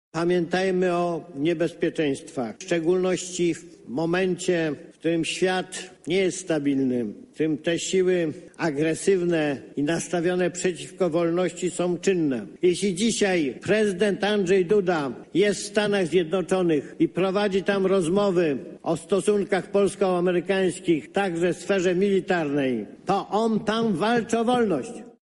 Konwencja Forum Młodych PiS za nami.
Obóz dobrej zmiany na czele z Andrzejem Dudą to obóz wolności – przekonywał prezes PiS Jarosław Kaczyński.
Głównym punktem programu był występ Jarosława Kaczyńskiego, który w swoich przemówieniach nawiązywał do ekologii, wolności czy kwestii nierówność płacowych wśród kobiet.